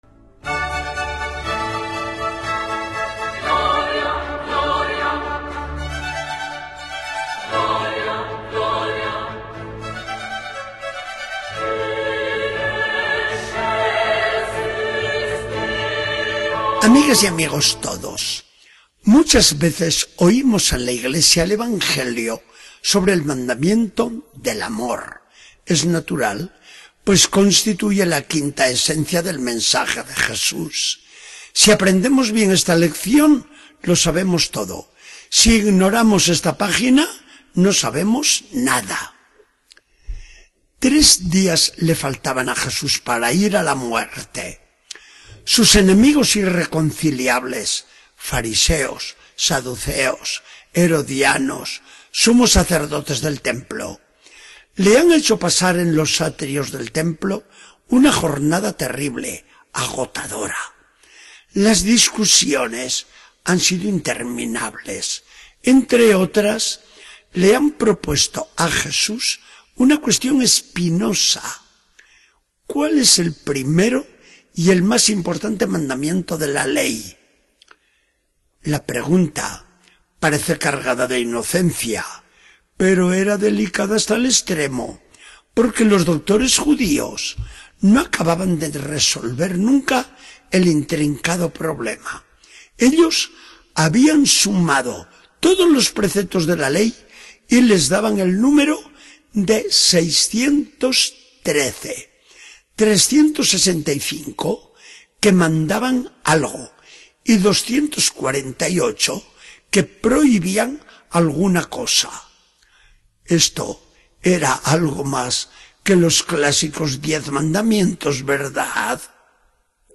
Charla del día 26 de octubre de 2014. Del Evangelio según San Mateo 22, 34-40.